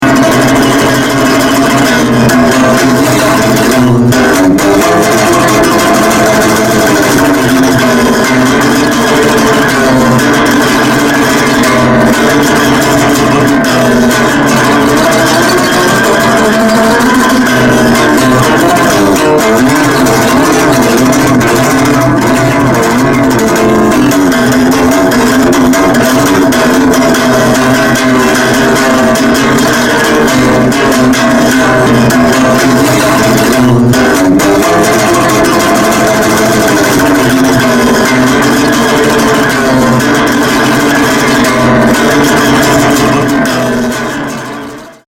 打楽器のごとく音と響きが乱れ飛びながら進んでいく様子が◎！
アコースティックギターのみで制作されたコチラの作品の続編が登場です！